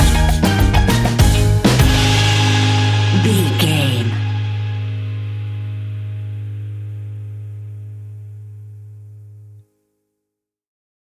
Summer sunshine calypso reggae music.
That perfect carribean calypso sound!
Ionian/Major
A♭
steelpan
drums
bass
brass
guitar